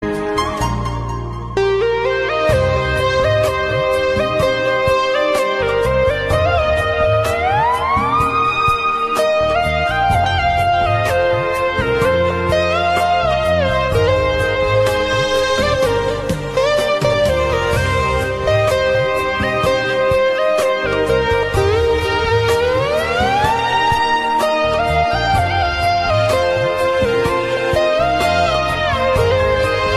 Hindi Bollywood Category